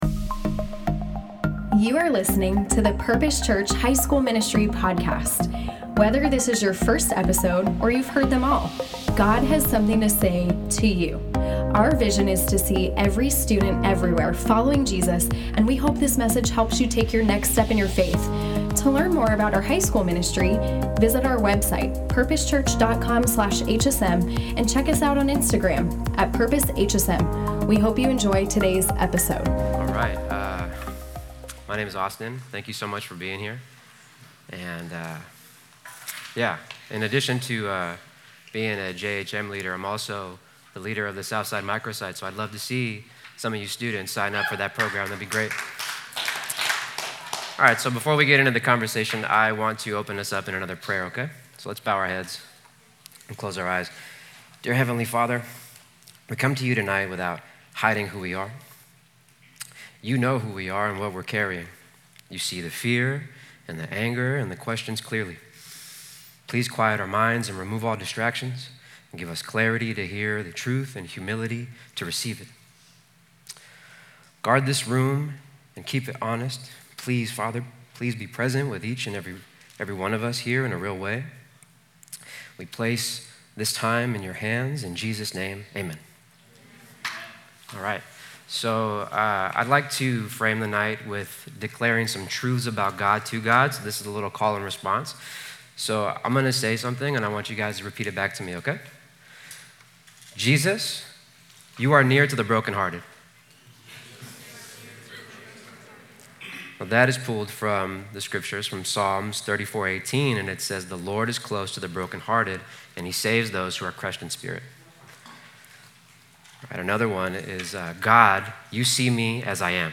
Q&A Panel | Uncharted: Crucial Conversations About Life’s Biggest Storms
We then get to hear from an incredible panel of HSM leaders on their personal experiences with anxiety or depression, as students submit anonymous questions for them to answer.